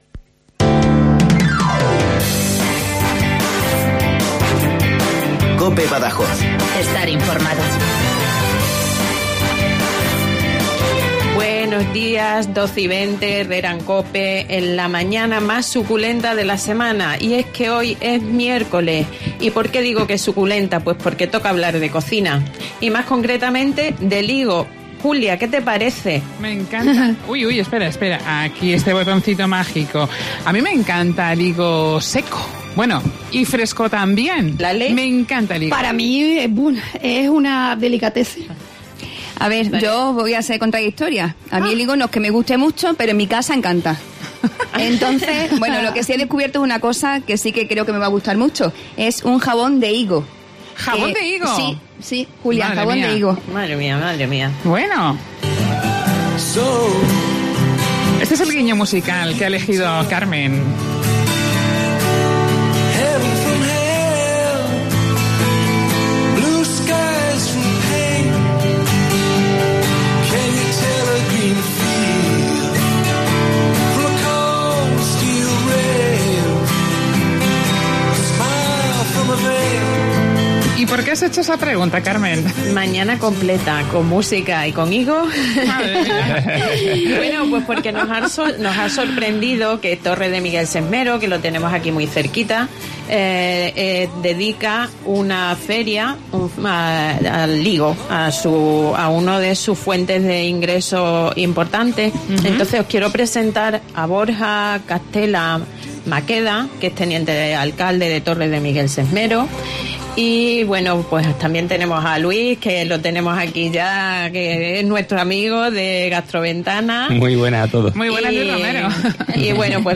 Hoy nuestro programa lo hemos dedicado a conocer detalles de una Feria que no nos la vamos a perder. La cuarta edición de la Fiesta del Higo en Torre de Miguel Sesmero de la que nos ha venido a hablar Borja Catela, teniente de alcalde de la localidad.